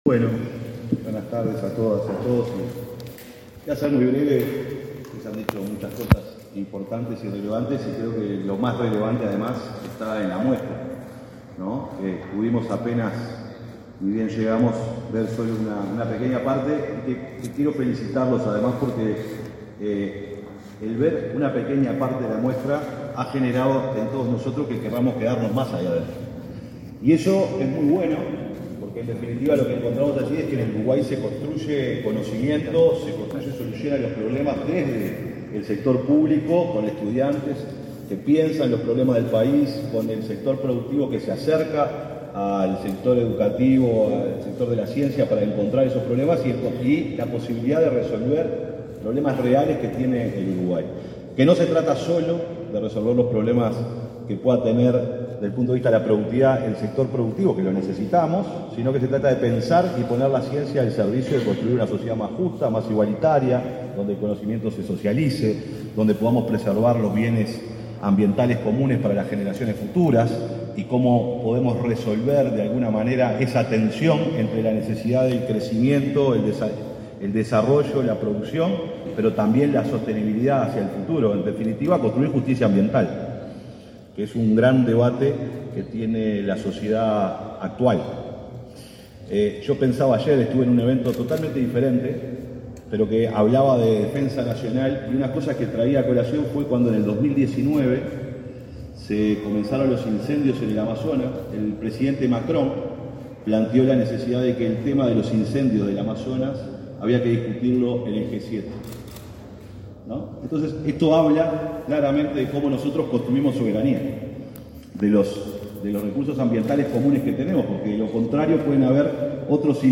Palabras del secretario de Presidencia, Alejandro Sánchez
En el marco de Ingeniería deMuestra 2025, que se desarrolla en la Facultad de Ingeniería, el secretario de la Presidencia, Alejandro Sánchez, disertó